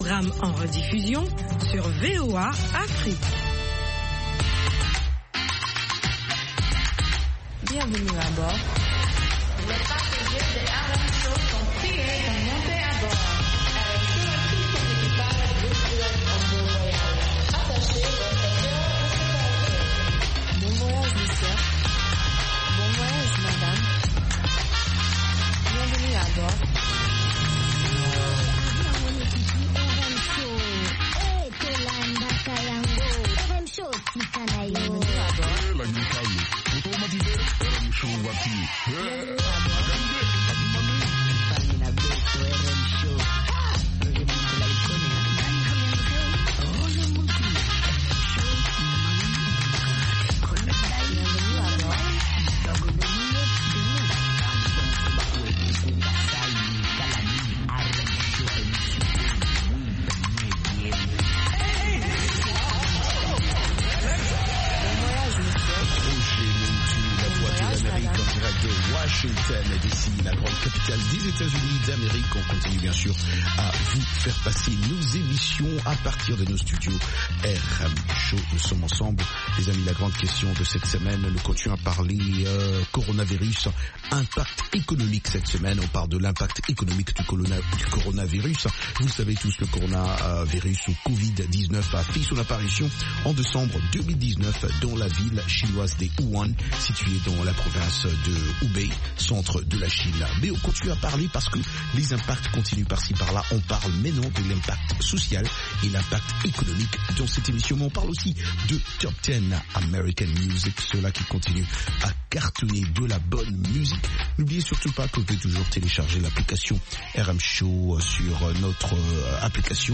Palmarès des chansons à la mode, en rapport avec les meilleures ventes de disques aux Etats-Unies